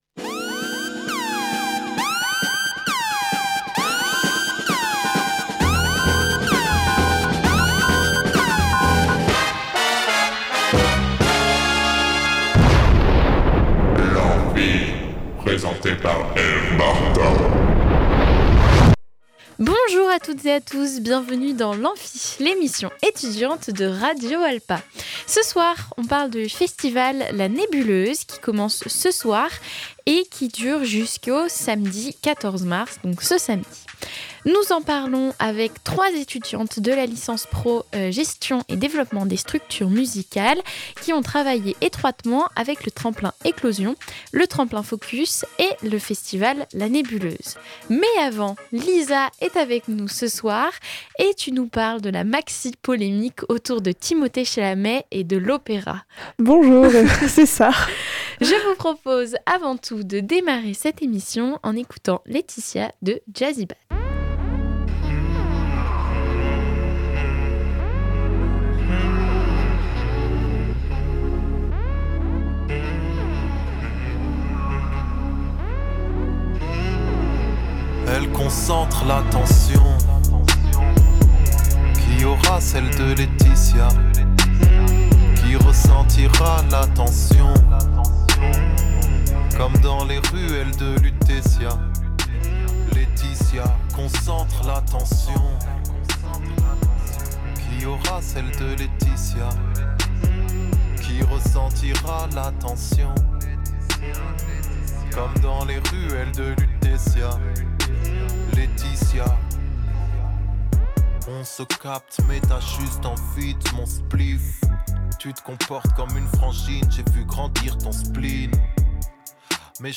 Ce soir dans l’Amphi on parlait du Festival la Nébuleuse avec trois étudiantes de la licence pro GDSM, qui ont travaillé étroitement avec le Tremplin Éclosion, le tremplin Focus et...